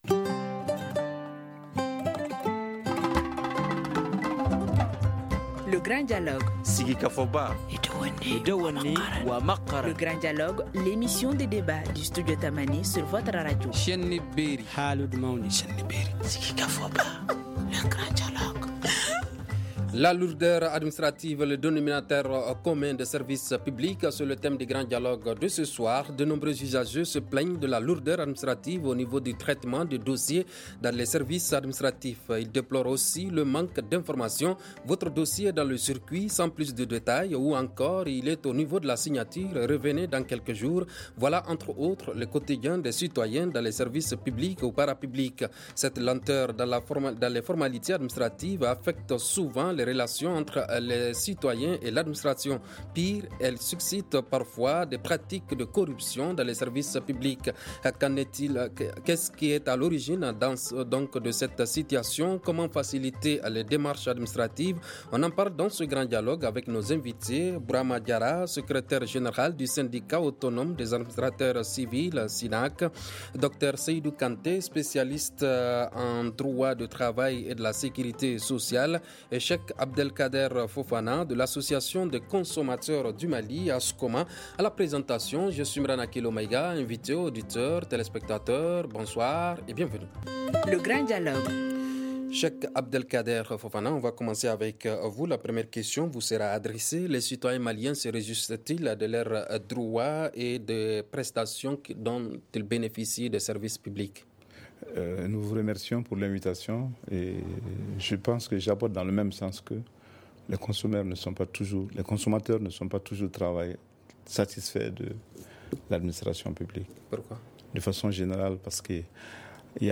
On en parle dans ce grand dialogue avec nos invités :